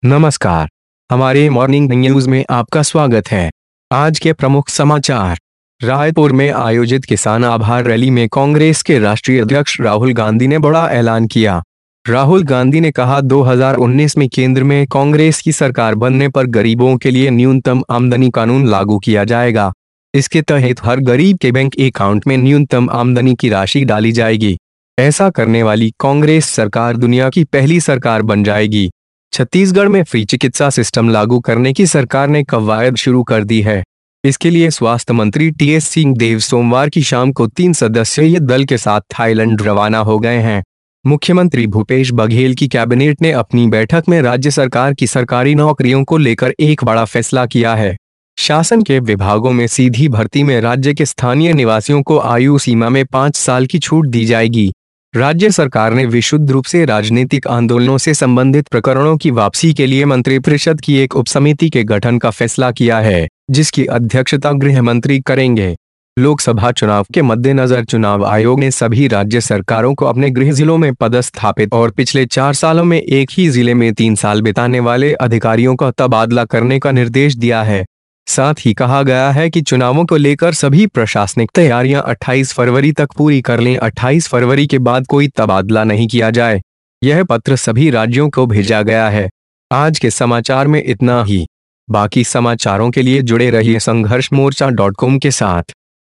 29 जनवरी Morning News: राहुल गांधी का बड़ा ऐलान गरीबों को न्यूनतम आय की गारंटी मिलेगी,छत्तीसगढ़ के युवाओं को सरकारी नौकरी में 5 साल की छूट